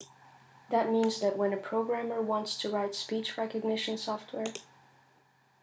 VoxForge recordings and a typical